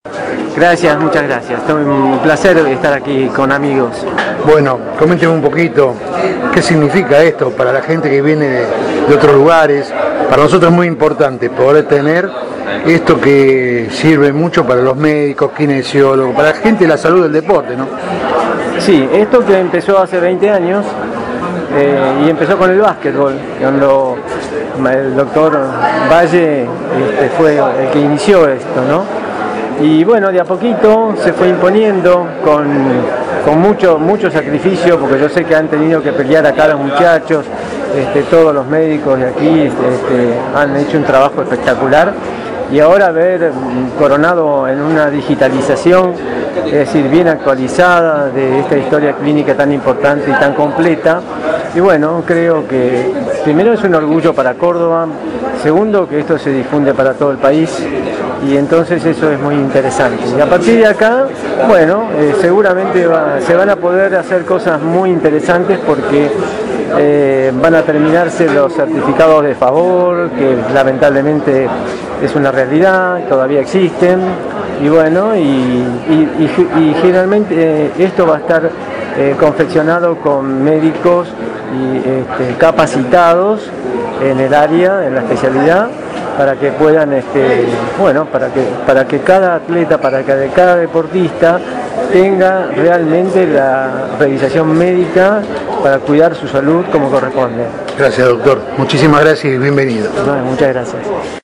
Córdoba y la modernización de la salud deportiva. Voces de los protagonistas de una Jornada Histórica en el Polo Deportivo Kempes.